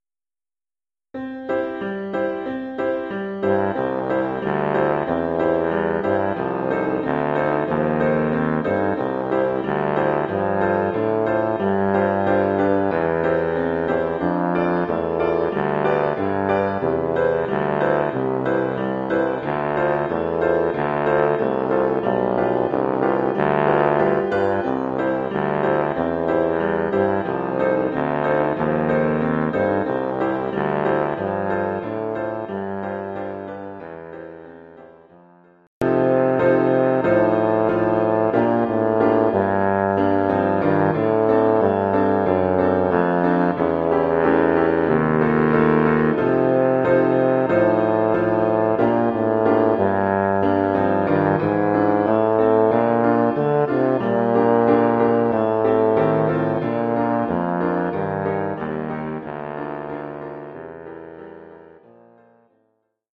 1 titre, tuba basse et piano : conducteur et partie de tuba
Oeuvre pour tuba basse et piano.